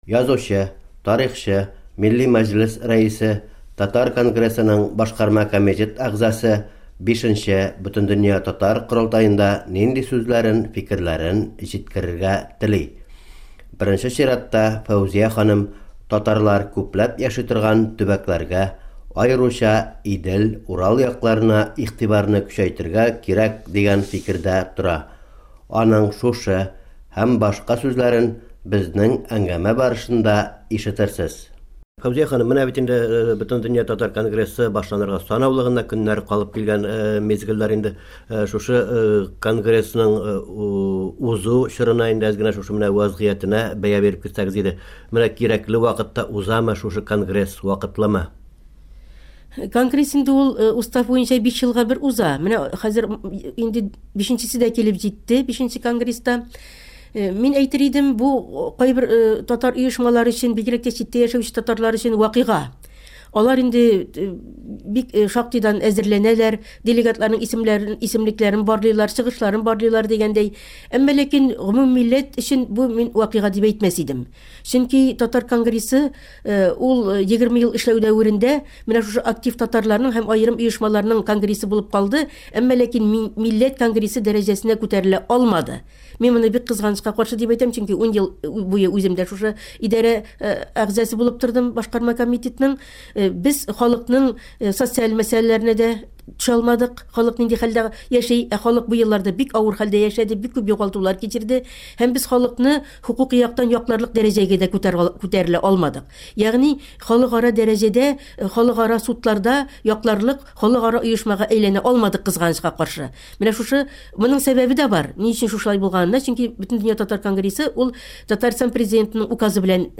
Фәүзия Бәйрәмова белән конгресс корылтае алдыннан әңгәмә